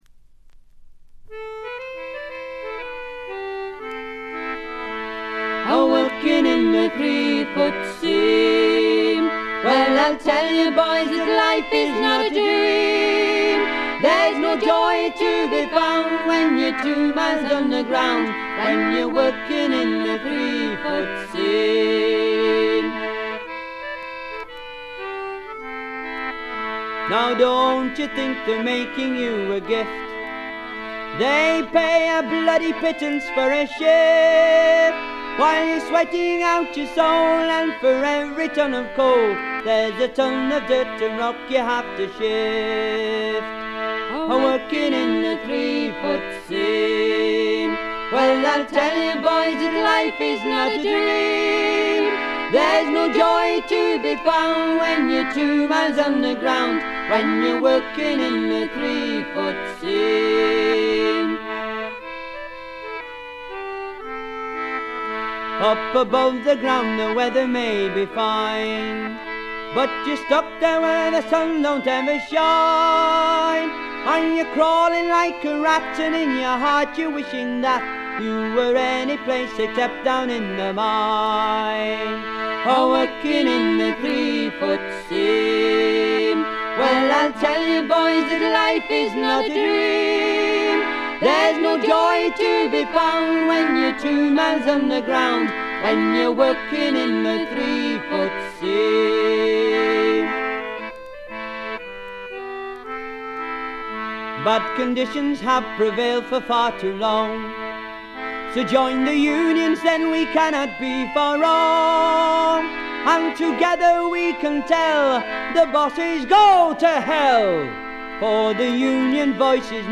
部分試聴ですが、ほとんどノイズ感無し。
若々しさ溢れるヴォーカルがとてもよいですね。
ごくシンプルな伴奏ながら、躍動感が感じられる快作！
コンサーティナの哀愁と郷愁をかきたてる音色がたまりません。
試聴曲は現品からの取り込み音源です。
English concertina